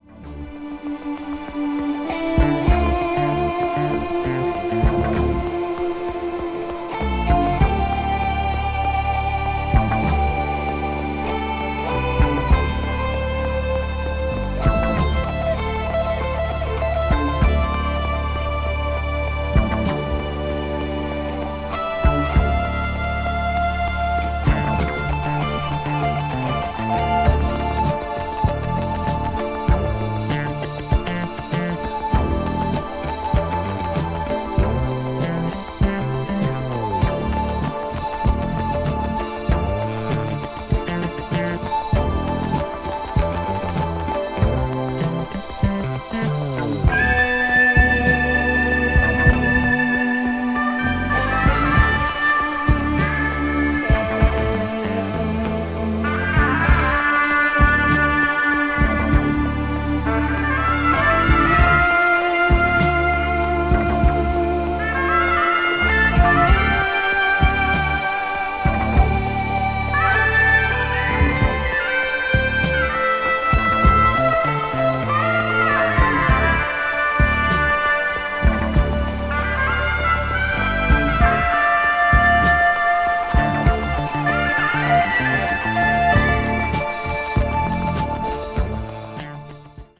electronic   progressive rock   symphonic rock   synthesizer